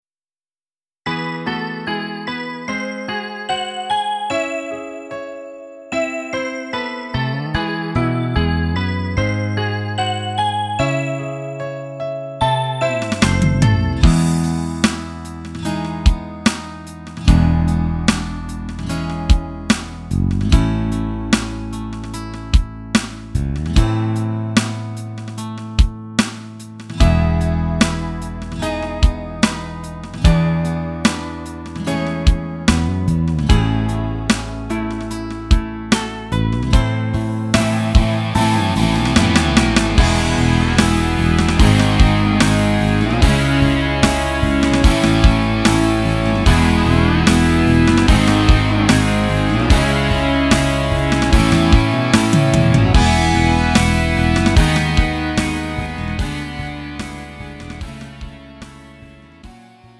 음정 원키 3:30
장르 가요 구분